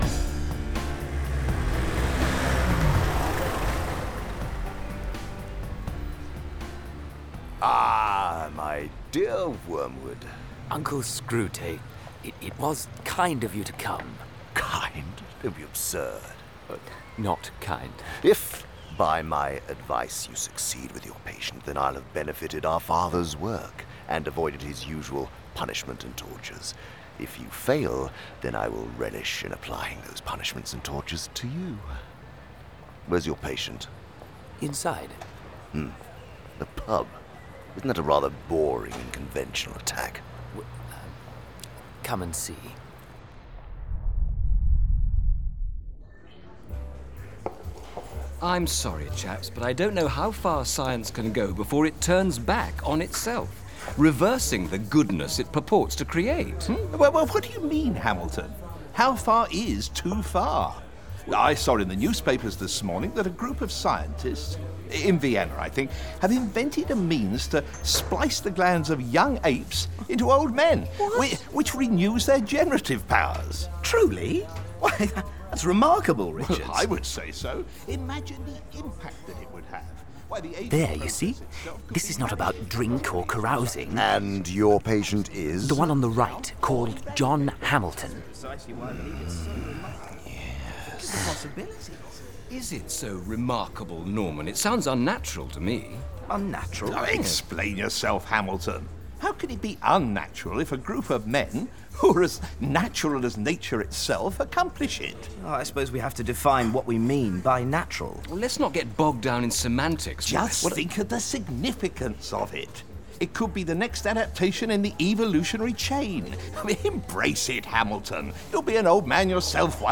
First Ever Full-cast Dramatization of the Diabolical Classic (Radio Theatre)
The style and unique dark humor of The Screwtape Letters are retained in this full-cast dramatization, as is the original setting of London during World War II. The story is carried by the senior demon Screwtape played magnificently by award-winning actor Andy Serkis (“Gollum” in Lord of the Rings) as he shares correspondence to his apprentice demon Wormwood. All 31 letters lead into dramatic scenes, set in either Hell or the real world with humans―aka “the patient,” as the demons say―along with his circle of friends and family.
4.30 Hrs. – Dramatized